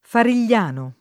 Farigliano [ faril’l’ # no ]